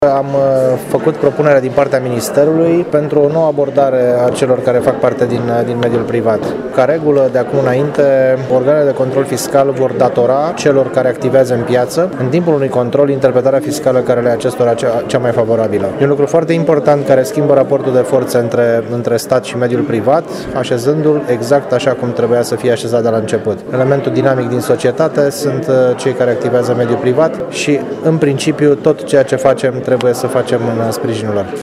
Secretarul de stat Călin Beciu a declarat astăzi, la Tîrgu-Mureș, în cadrul unei dezbateri dedicate mediului de afaceri, că organele de control fiscale vor aplica interpretarea cea mai favorabilă contribuabilului.